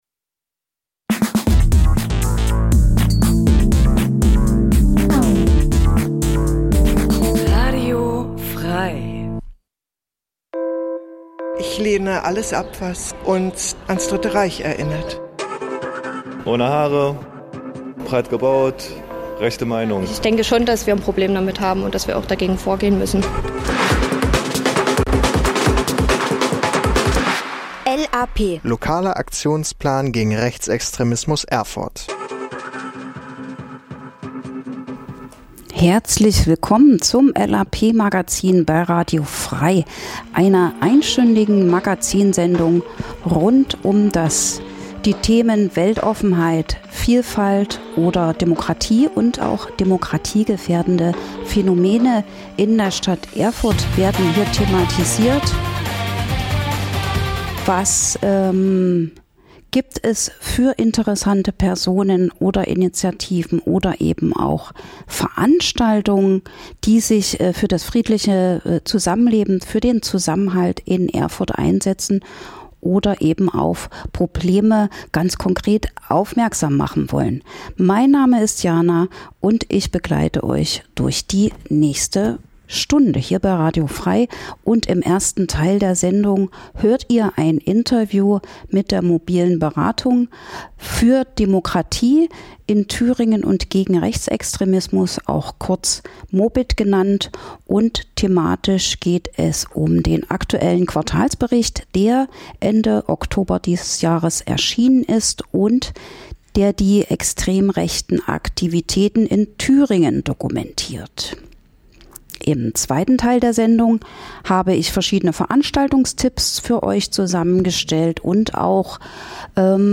In der Sendung h�rt ihr verschiedene Beitr�ge rund um Demokratie und gegen Rechts, z. B. - Interviews zu aktuellen Themen - Veranstaltungshinweise - Musikrubrik "Coole Cover" --- Die Sendung l�uft jeden zweiten Mittwoch 11-12 Uhr (Wiederholung: Donnerstag 20 Uhr) und informiert �ber Themen, Projekte und Termine gegen Rechts in Erfurt und Umgebung.